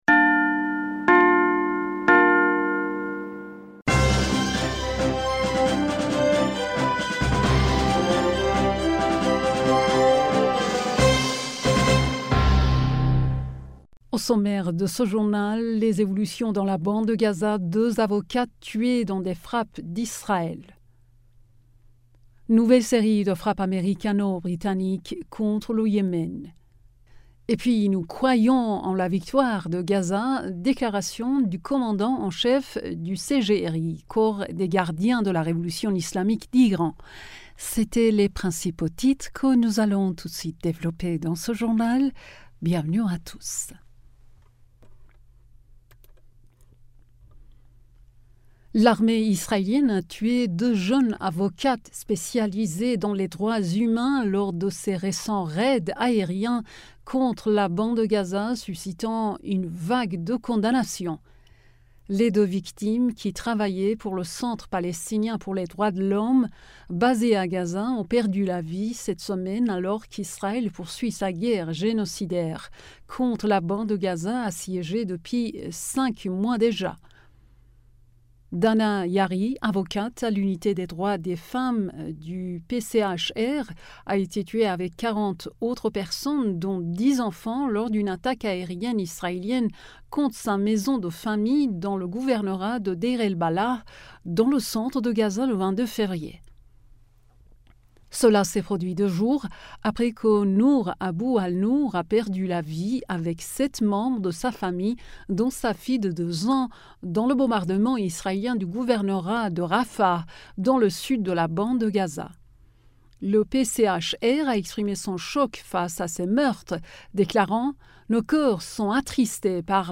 Bulletin d'information du 25 Fevrier 2024